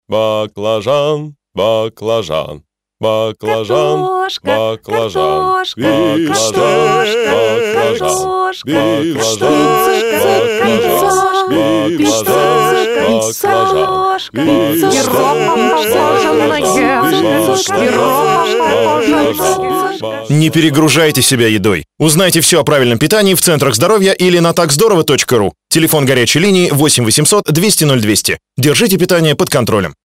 Вид рекламы: Радиореклама
"перегрузка" приводит к диссонансу